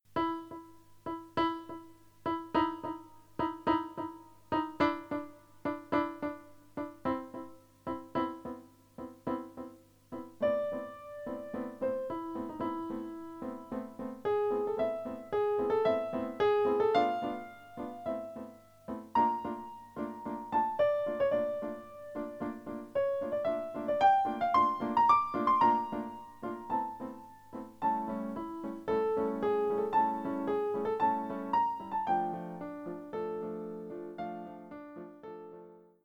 Instrumentation: Piano